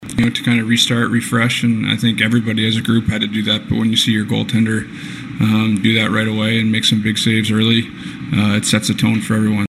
Crosby said the Penguins got a lot of energy from goaltender Tristan Jarry, who was solid from the start.